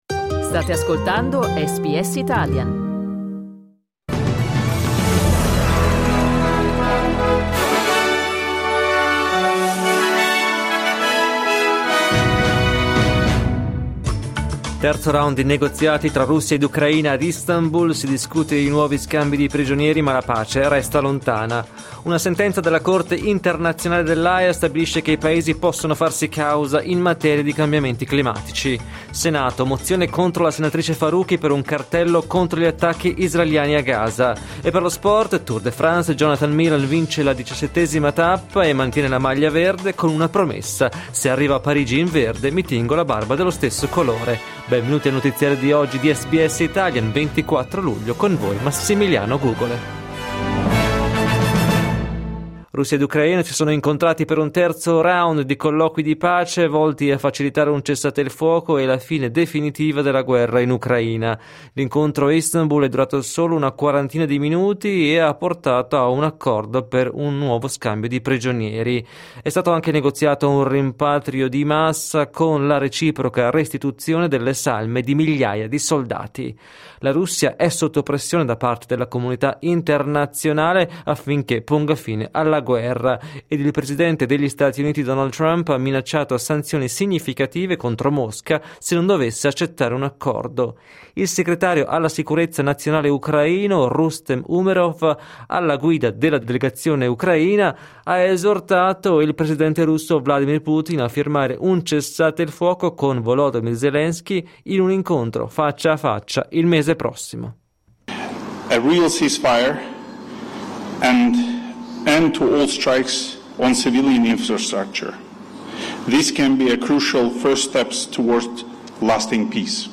Giornale radio giovedì 24 luglio 2025
Il notiziario di SBS in italiano.